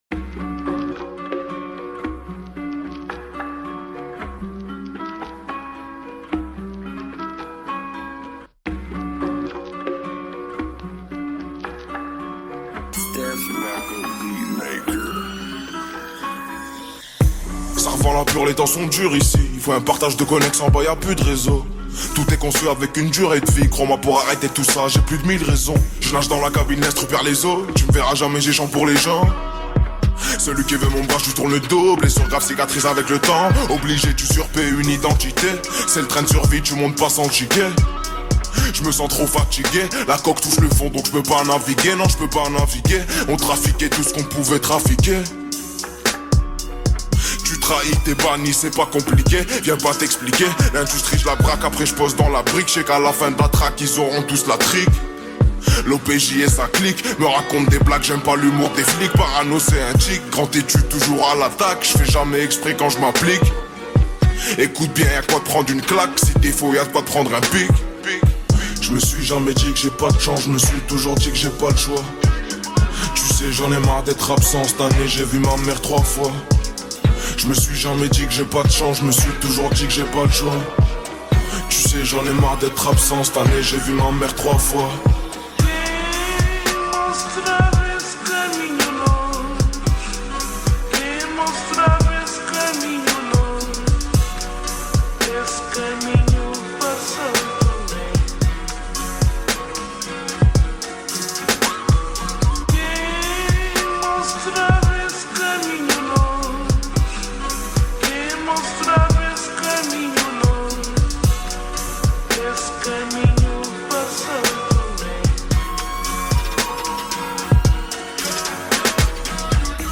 french rap Télécharger